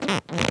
foley_leather_stretch_couch_chair_02.wav